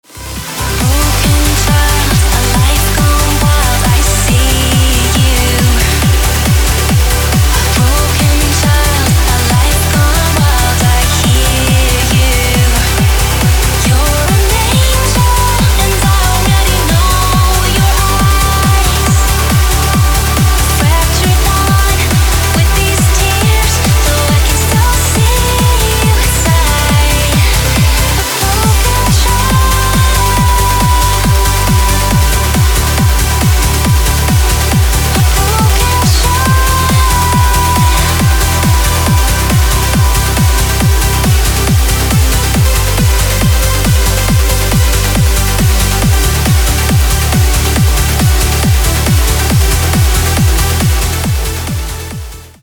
• Качество: 256, Stereo
громкие
женский вокал
dance
Electronic
электронная музыка
club
красивый женский голос
Trance
vocal trance
вокал